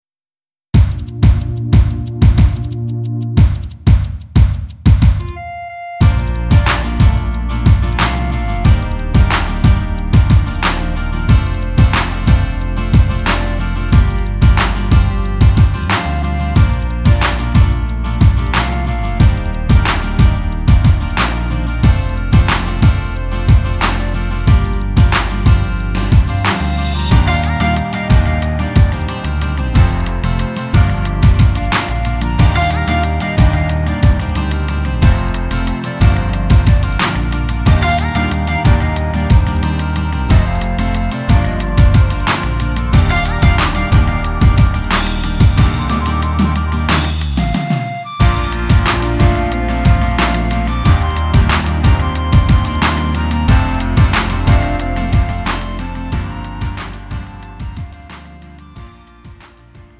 음정 원키
장르 가요 구분 Lite MR